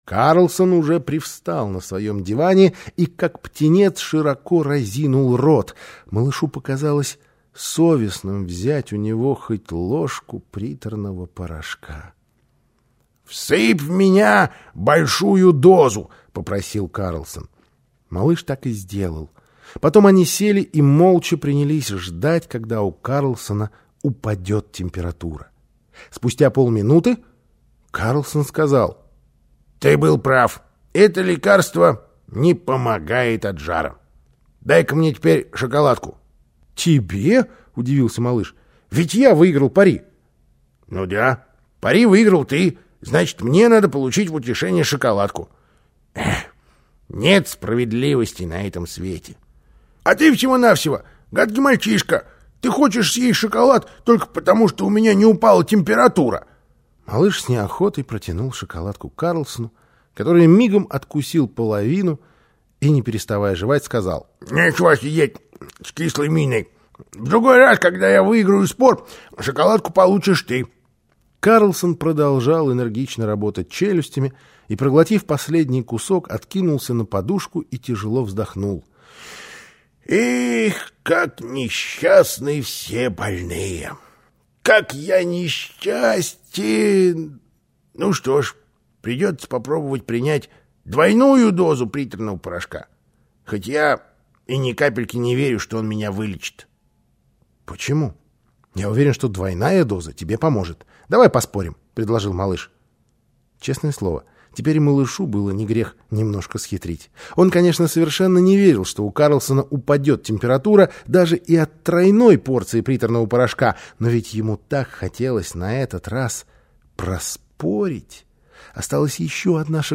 Аудиосказка «Карлсон, который живёт на крыше». Слушать онлайн либо скачать